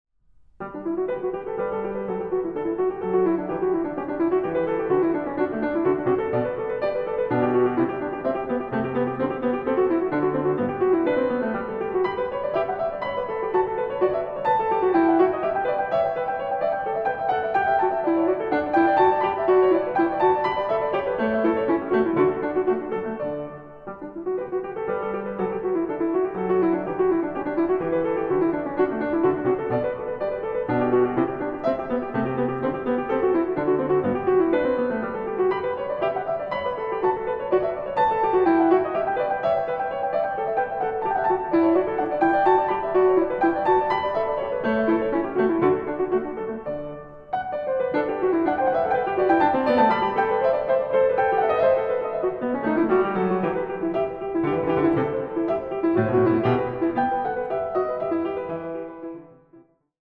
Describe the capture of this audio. Recording: Jesus-Christus-Kirche Berlin-Dahlem, 2024